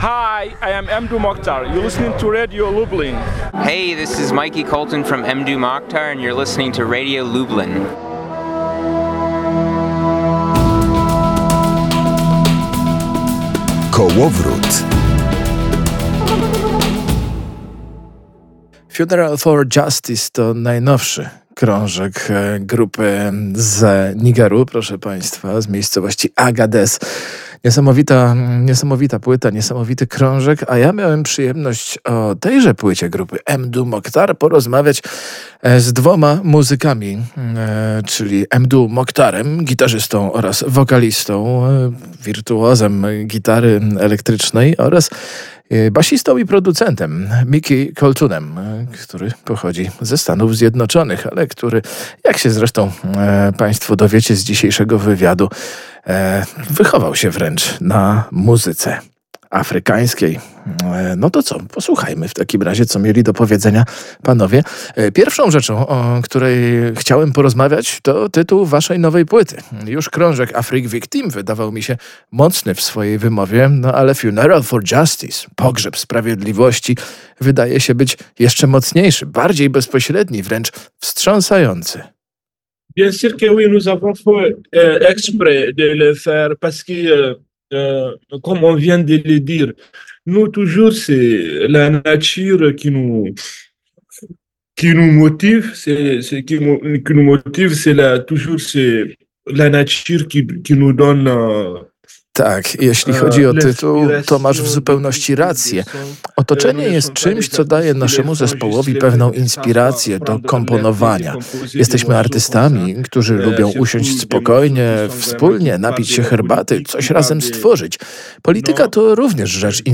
Posłuchaj wywiadu: Pierwszą rzeczą o której chciałem porozmawiać, to tytuł Waszej nowej płyty.